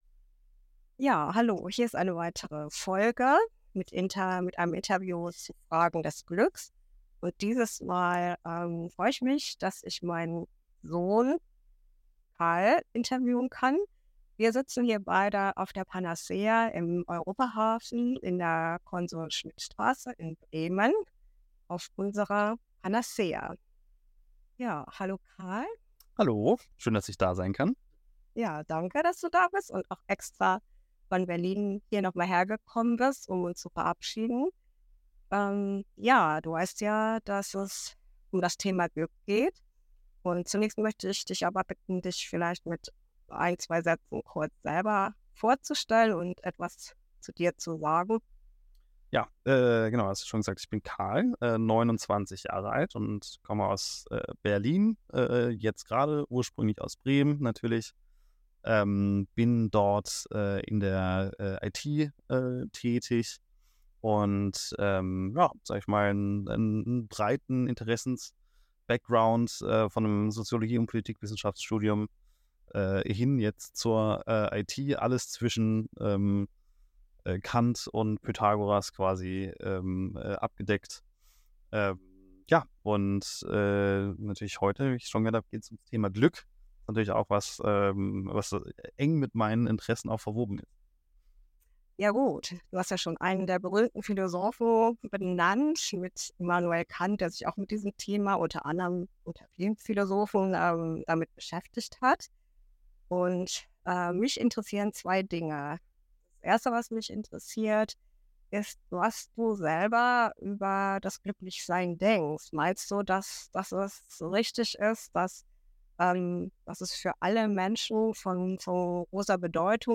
Interviewreihe zum Thema Glück